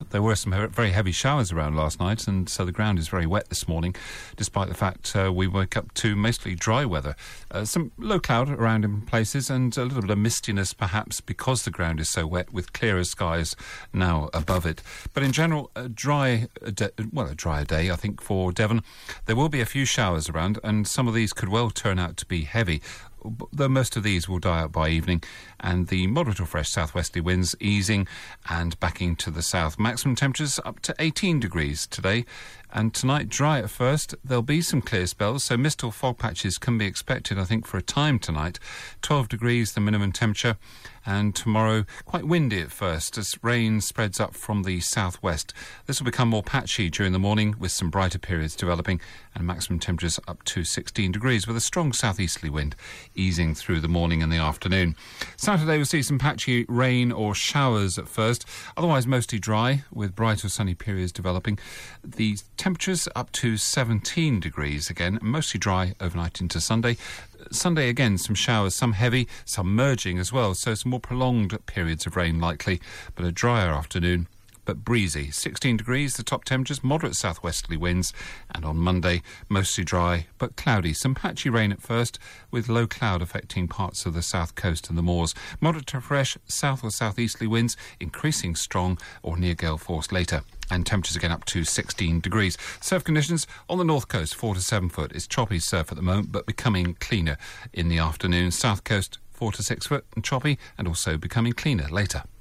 5 day forecast for Devon from 8.35AM on 17 October